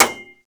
Index of /server/sound/vj_impact_metal/bullet_metal
metalsolid8.wav